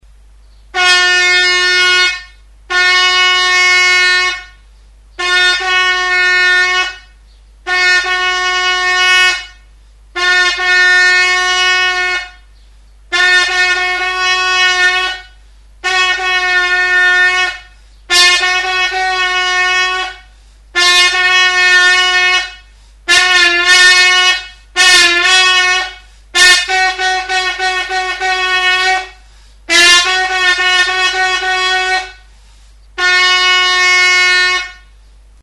Instrumentos de músicaCORNETA DE BARRO; Buztinezko korneta
Aerófonos -> Vibración labios (trompeta) -> Naturales (con y sin agujeros)
Grabado con este instrumento.
Zulo eta pistoirik gabeko buztinezko tronpeta naturala da.